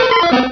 Cri de Tartard dans Pokémon Rubis et Saphir.